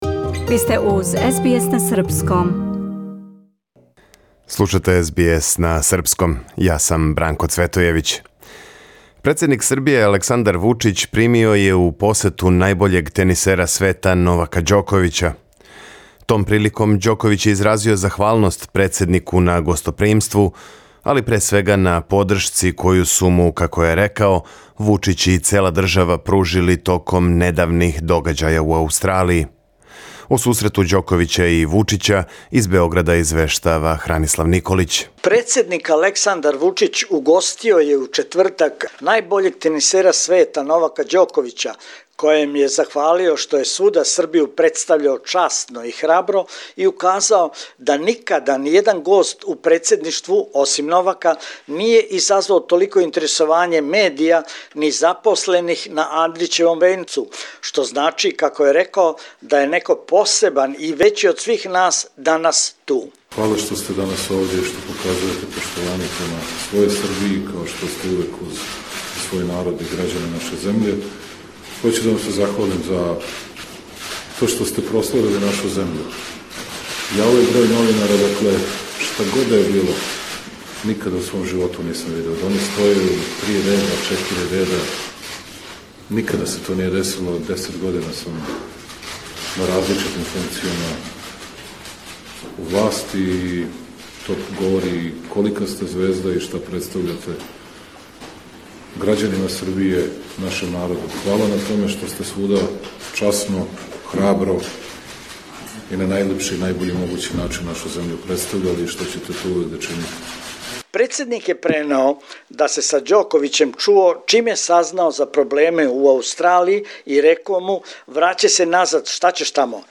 Програм емитован уживо 5. фебруара 2022. године
Ако сте пропустили нашу емисију, сада можете да је слушате у целини без реклама, као подкаст.